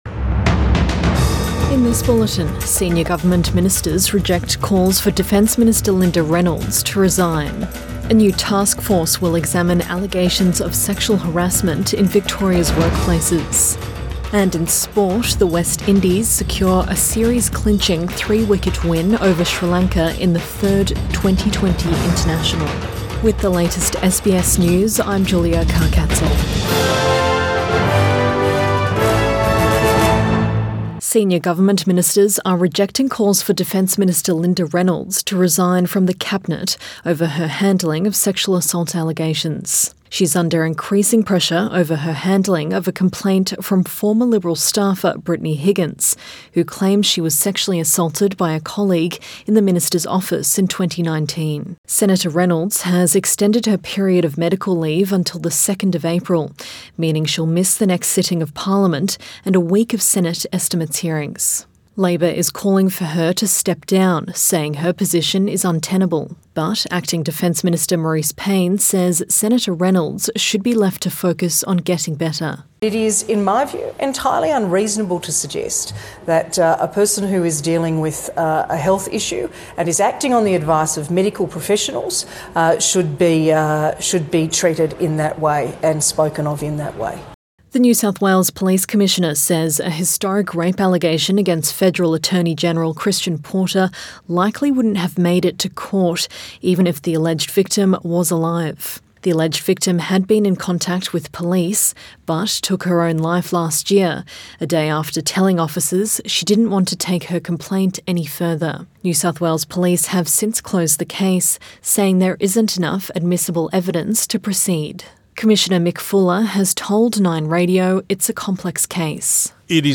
PM bulletin 8 March 2021